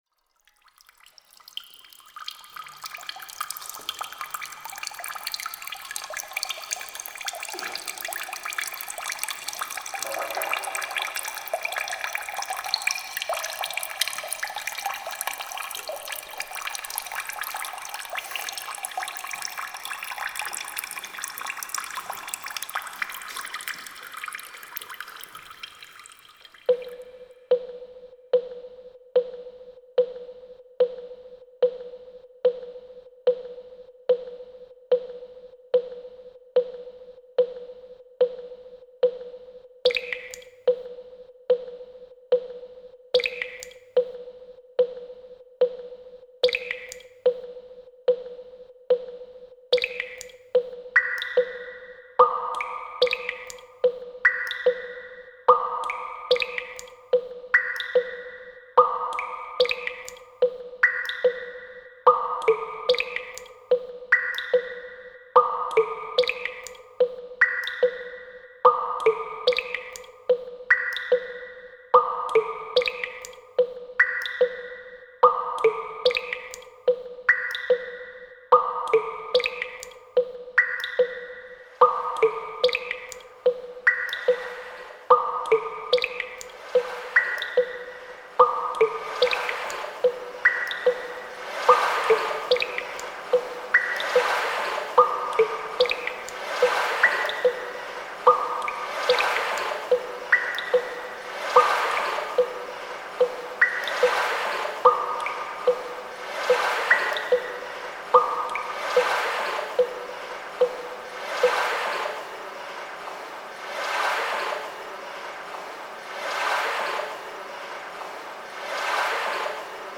R�sidence et atelier de cr�ation sonore� Pr�sentation sortie d'atelier le mardi 26 mars�
Montage audio r�alis� avec les r�sidents et les enfants